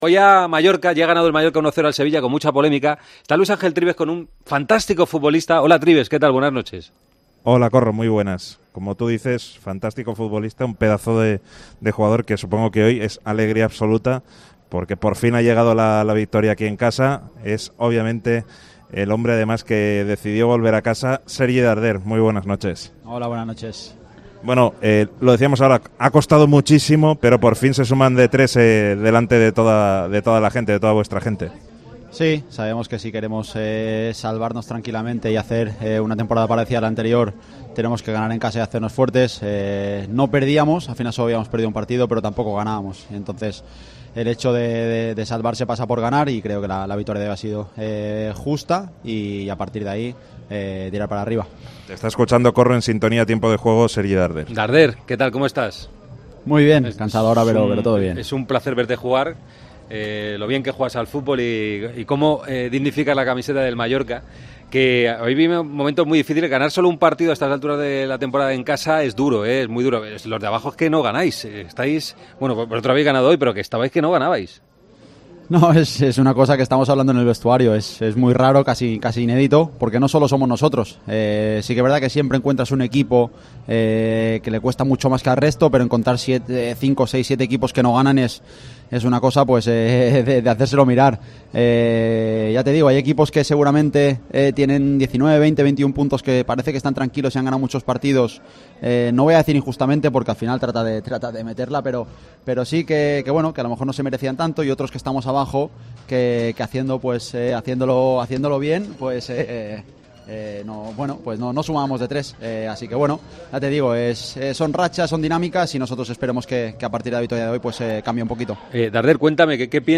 El jugador del Mallorca se pasó por Tiempo de Juego y habló sobre el gol anulado al Sevilla: "No se tienen que anular goles de esta manera, pero por una norma clara que tenemos..."
Con Paco González, Manolo Lama y Juanma Castaño